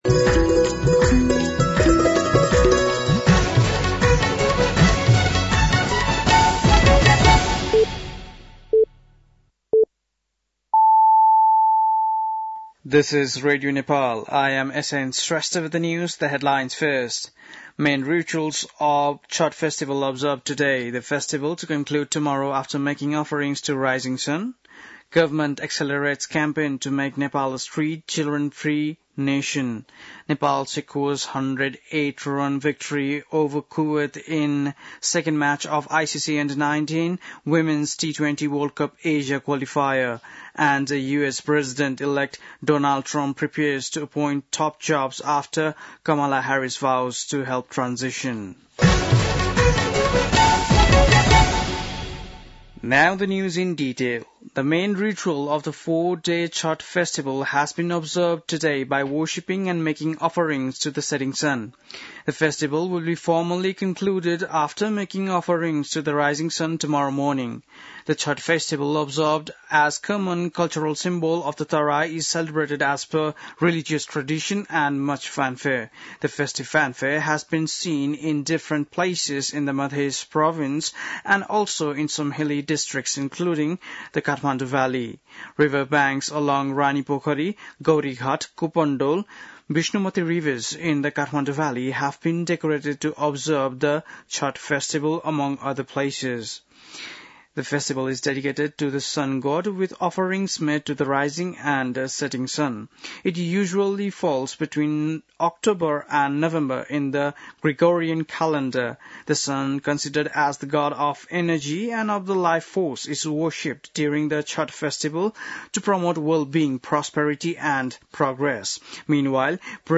बेलुकी ८ बजेको अङ्ग्रेजी समाचार : २३ कार्तिक , २०८१